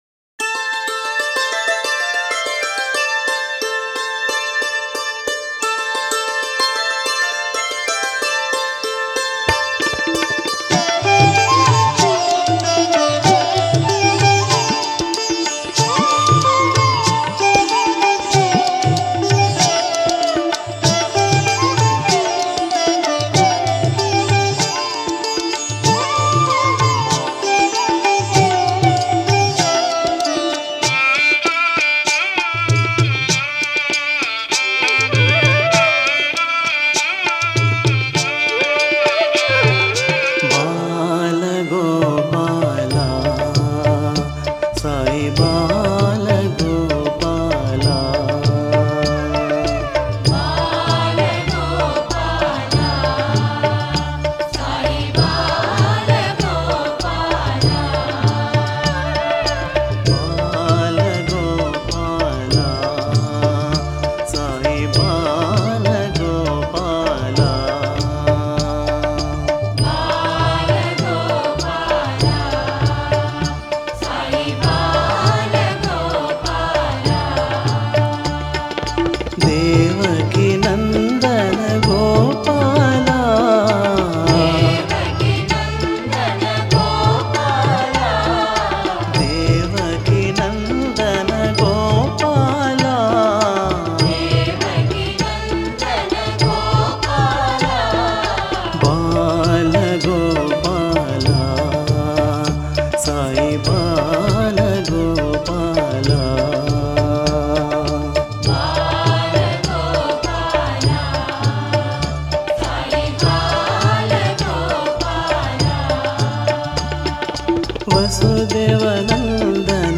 Author adminPosted on Categories Krishna Bhajans